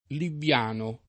Libbiano [ libb L# no ]